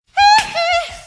Fx Michael Hee Hee Sound Effect Download: Instant Soundboard Button